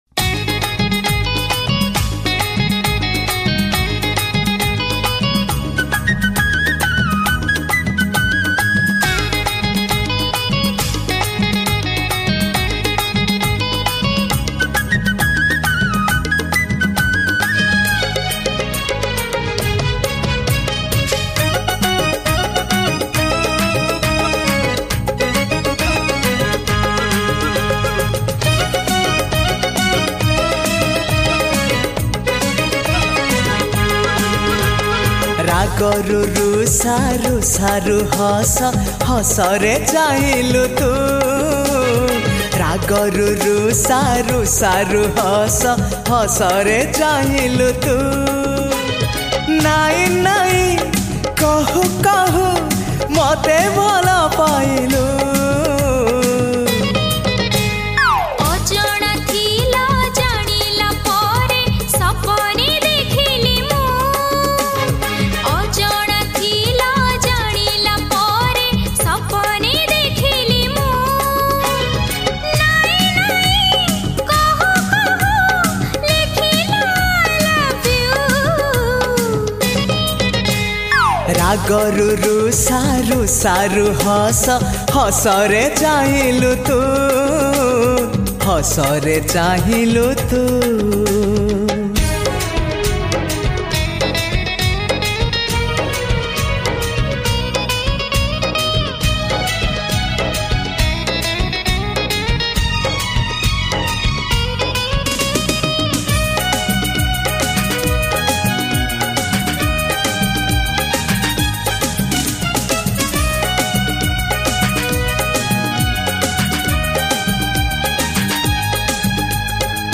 Romantic Song
Odia Songs